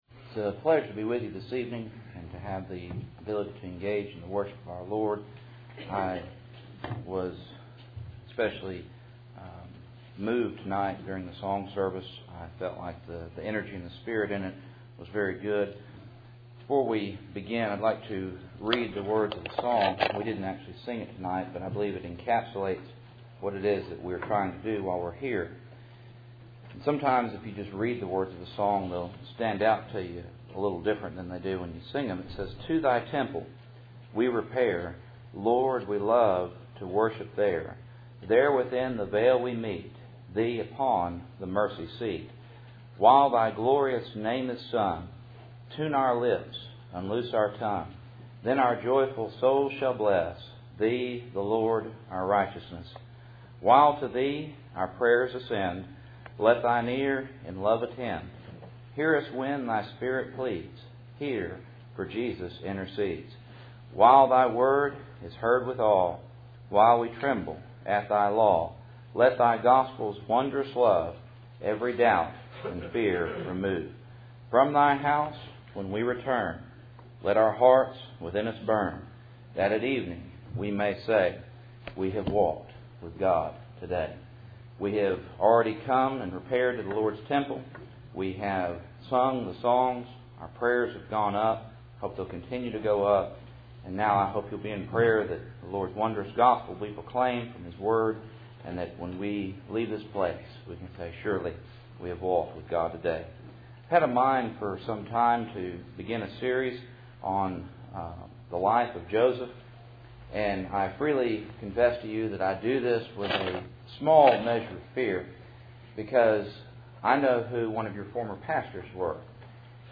Cool Springs PBC Sunday Evening